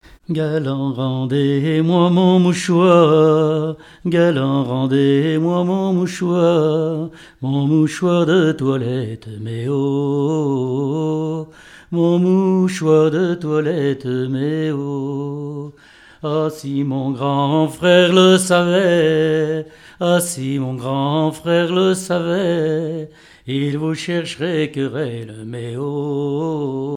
Genre strophique
répertoire de chansons, et d'airs à danser
Pièce musicale inédite